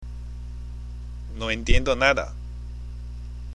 （ノ　エンティエンド　ナダ）